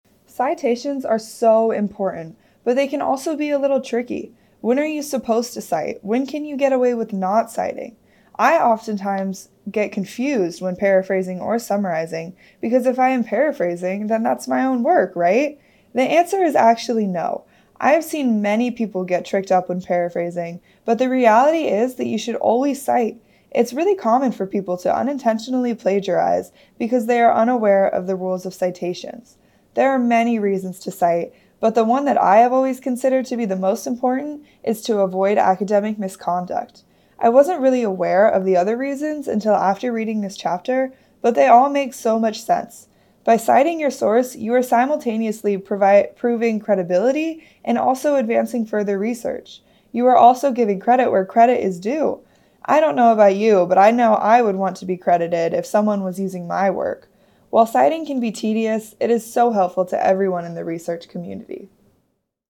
Student Voices